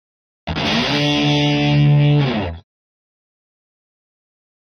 Guitar Heavy Metal Finale Chord 3